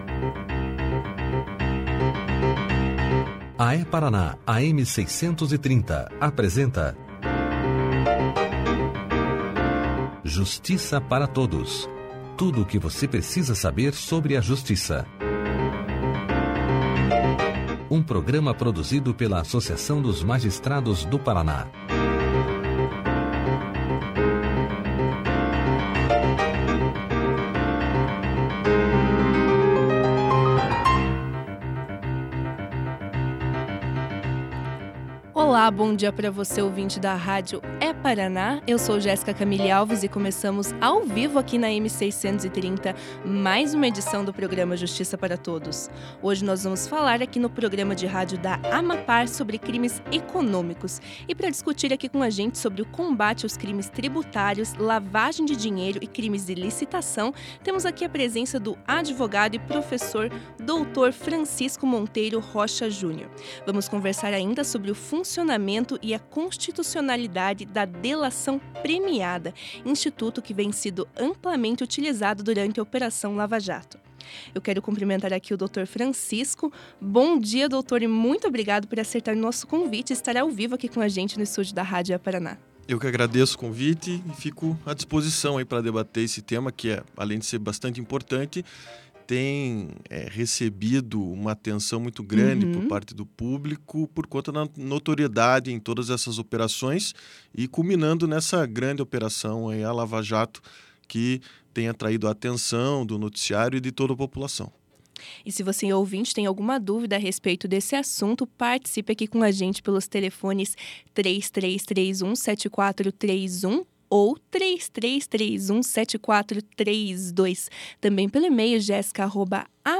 Além disso, o funcionamento e a constitucionalidade da delação premiada, instituto que vem sendo amplamente utilizado durante a operação Lava Jato, também foram abordados durante a entrevista.